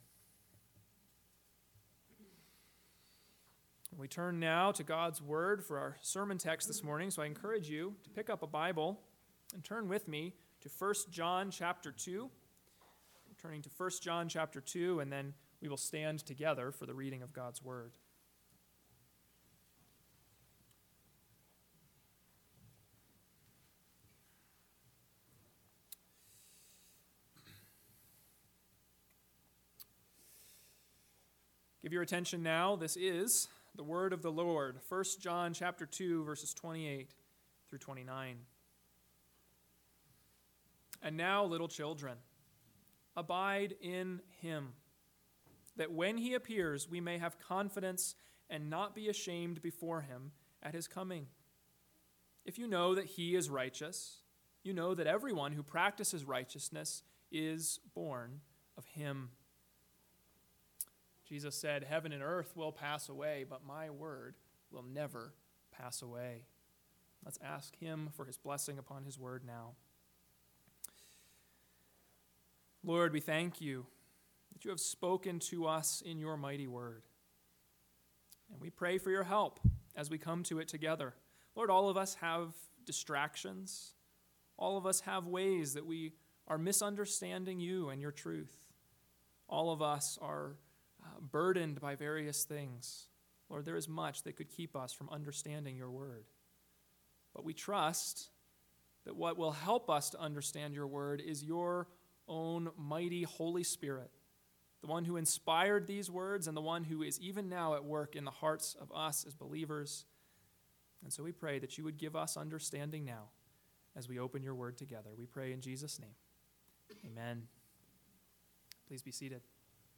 AM Sermon